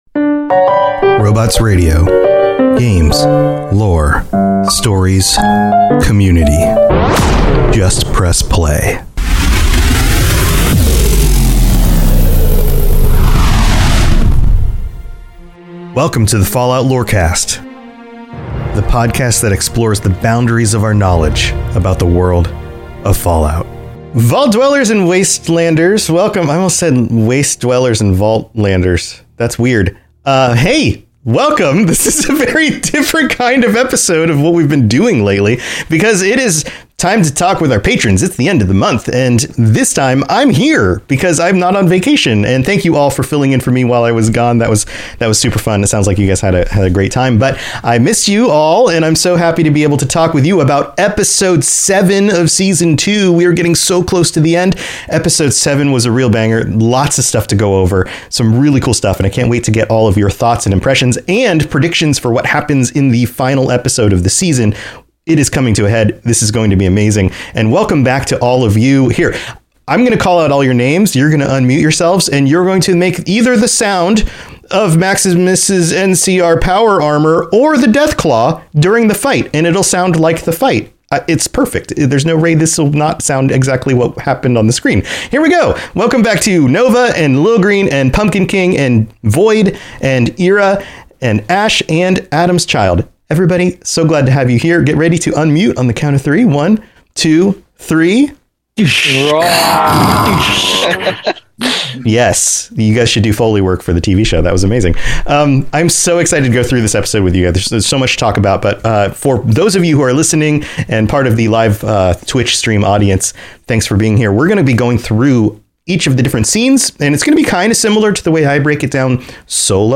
This episode was EPIC, so we're doing an extra long episode with the patrons to discuss all the details and try to predict how this season will end.